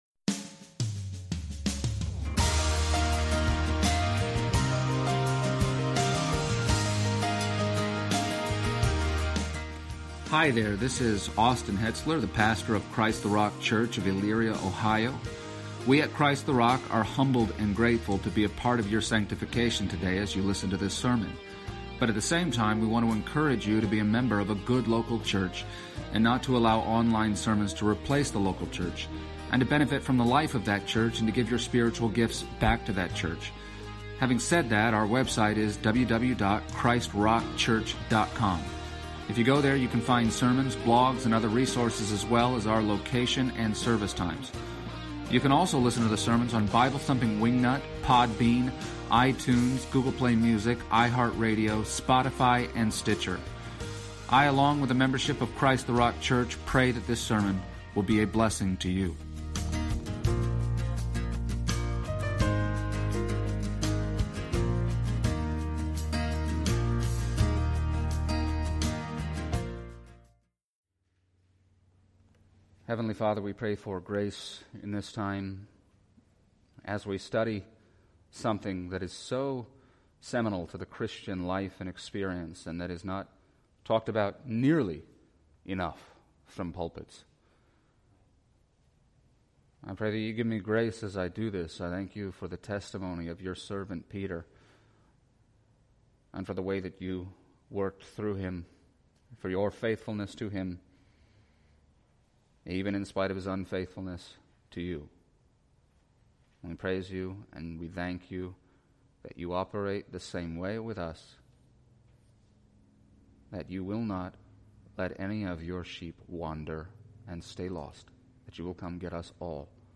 Passage: John 21:15-17 Service Type: Sunday Morning